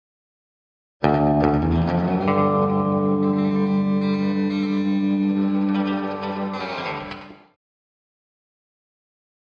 Electric Guitar Twang - Texas Melody 1, (Slide Guitar)